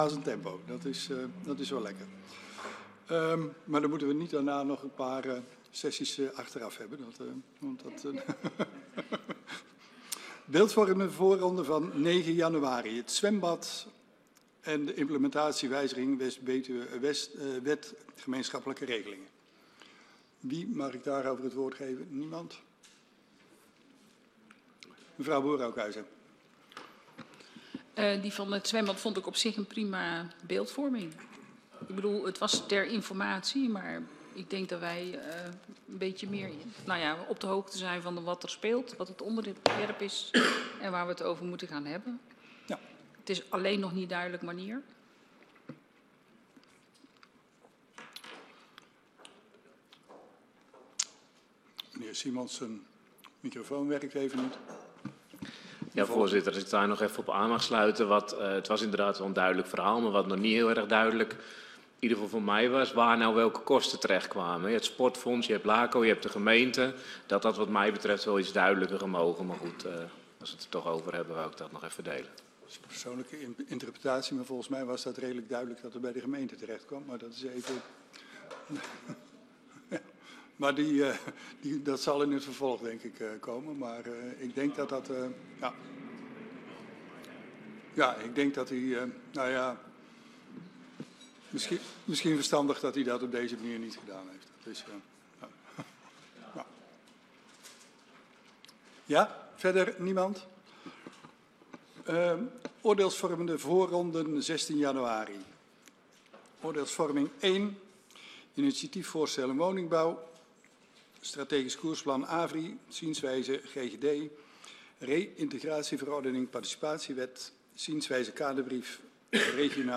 Locatie: Brandweerzaal Voorzitter: Henk de Man